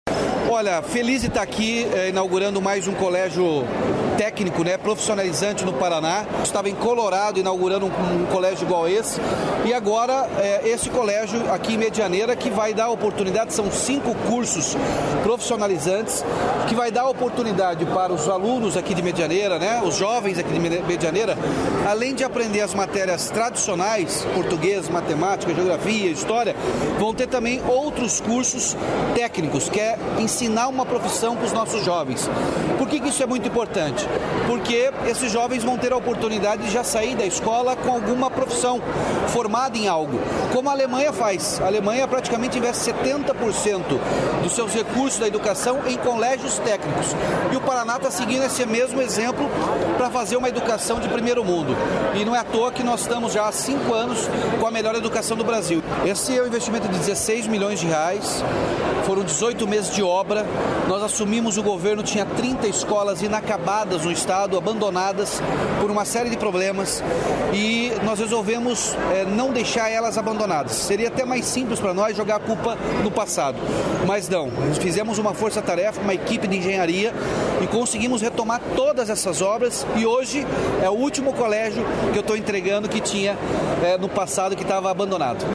Sonora do governador Ratinho Junior sobre novo CEEP em Medianeira